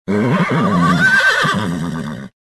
Звуки ржания лошадей
На этой странице собрана коллекция натуральных звуков ржания лошадей.